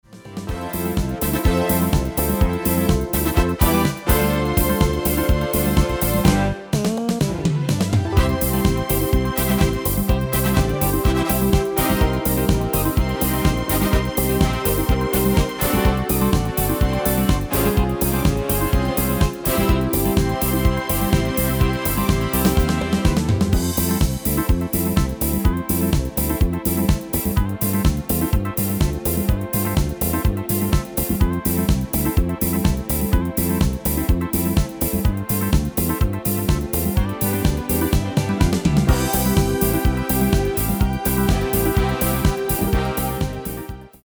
Genre: Disco
Toonsoort: C
Demo's zijn eigen opnames van onze digitale arrangementen.